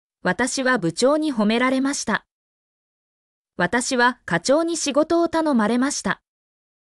mp3-output-ttsfreedotcom-2_0I1hqtg9.mp3